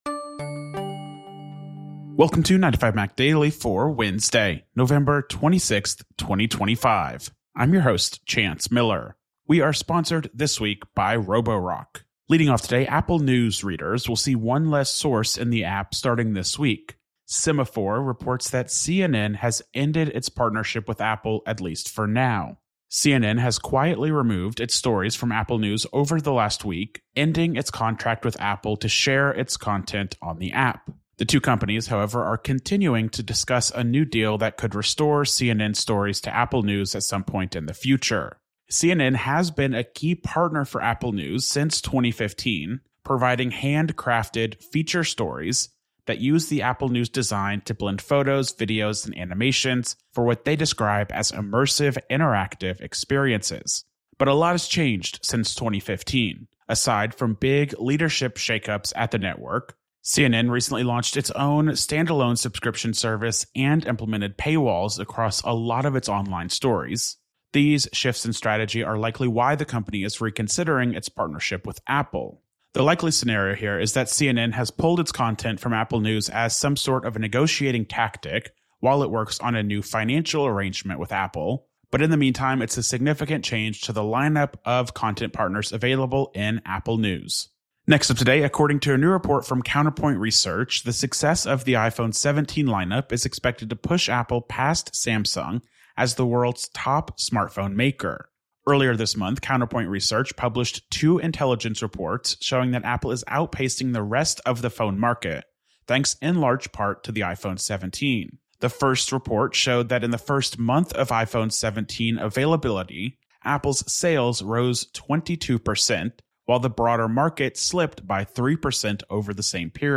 استمع إلى ملخص لأهم أخبار اليوم من 9to5Mac. 9to5Mac يوميا متاح على تطبيق iTunes وApple Podcasts, غرزة, TuneIn, جوجل بلاي، أو من خلال موقعنا تغذية RSS مخصصة لـ Overcast ومشغلات البودكاست الأخرى.